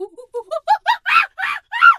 monkey_2_chatter_scream_05.wav